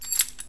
KeysPickUp.ogg